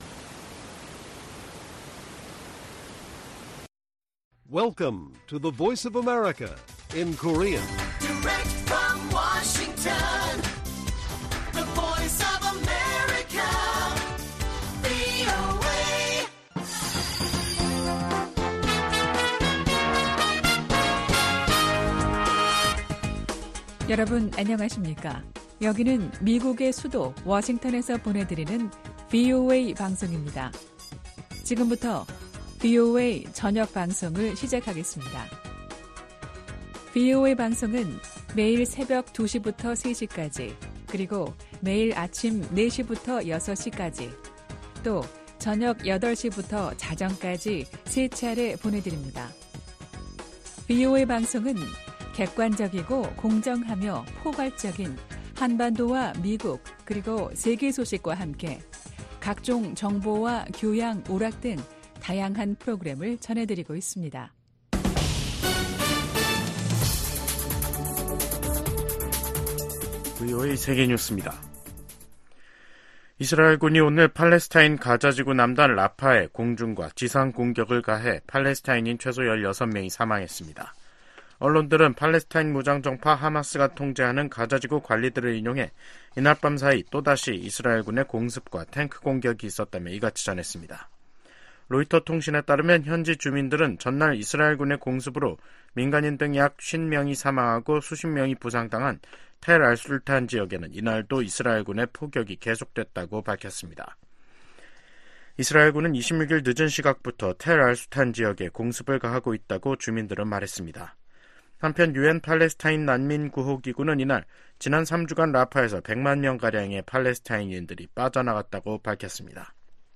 VOA 한국어 간판 뉴스 프로그램 '뉴스 투데이', 2024년 5월 28일 1부 방송입니다. 북한이 27일 밤 ‘군사 정찰위성’을 발사했지만 실패했습니다.